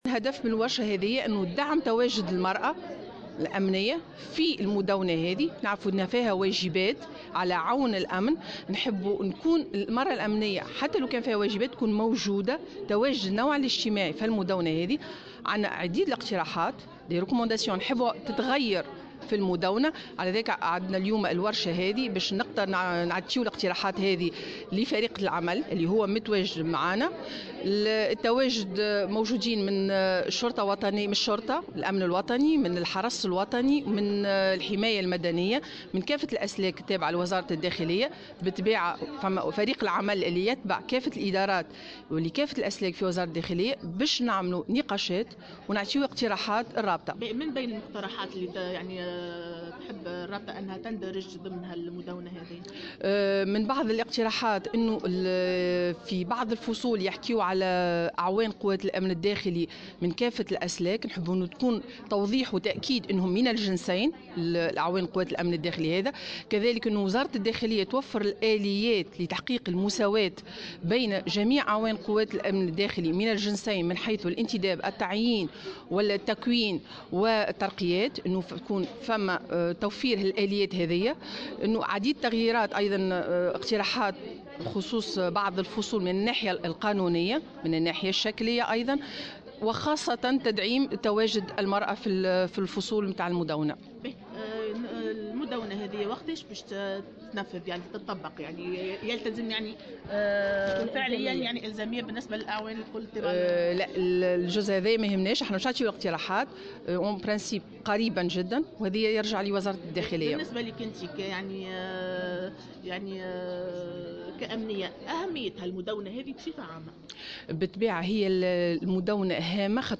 على هامش ورشة عمل نظمتها الرابطة لتقديم مقترحات تعديل بشأن المدونة